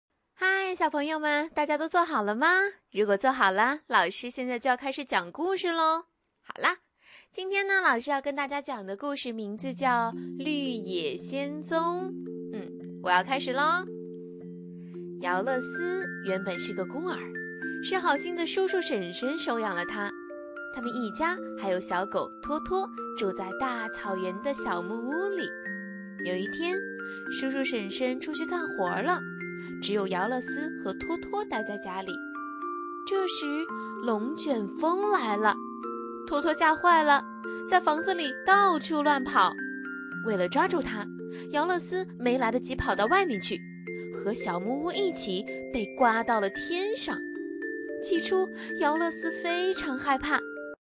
【女58号课件】儿童绘本（亲切可爱）